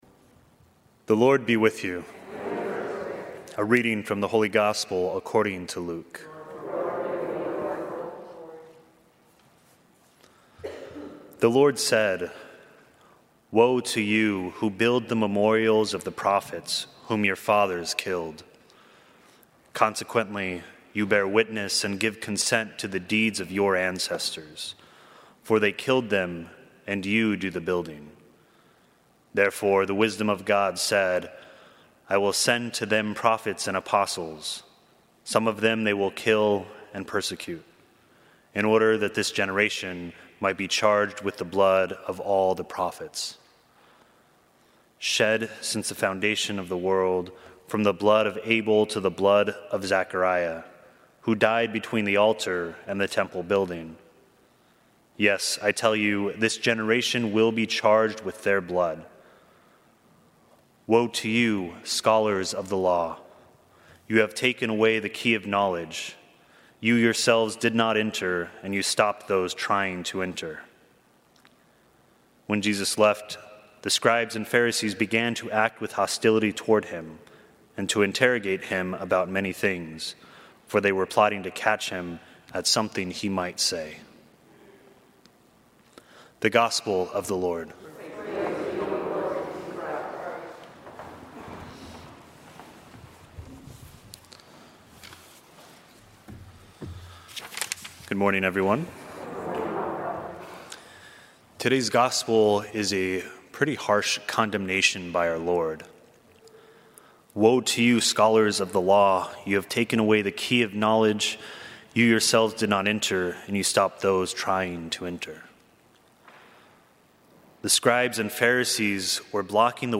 Gospel and Homily Podcasts